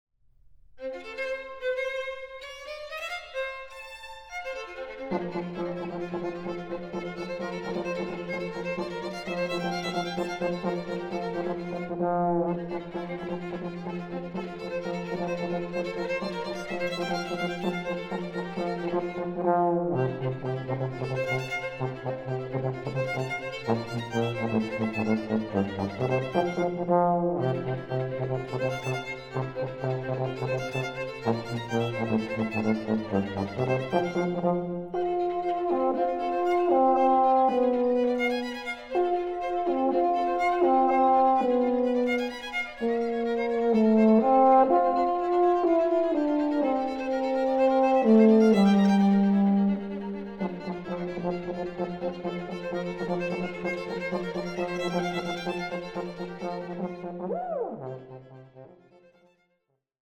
Version for Euphonium, Piano and Violin